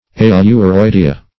Search Result for " ailuroidea" : The Collaborative International Dictionary of English v.0.48: Ailuroidea \Ai`lu*roid"e*a\, n. pl.